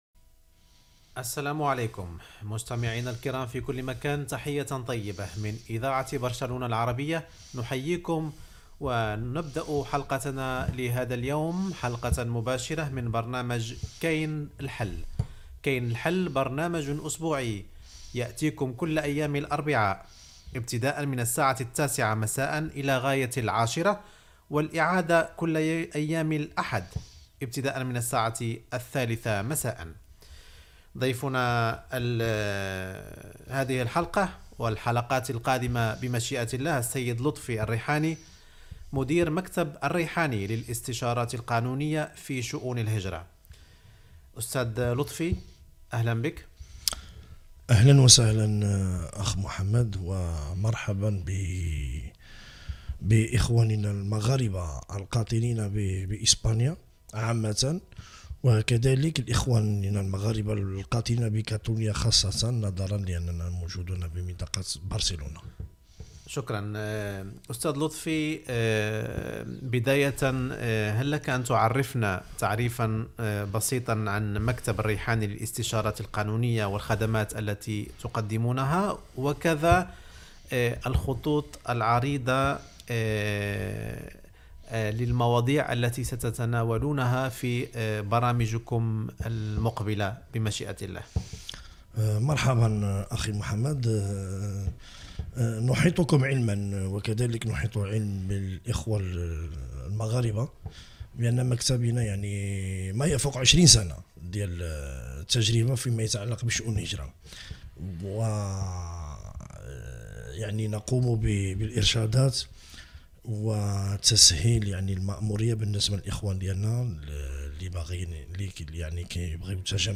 Entreteniment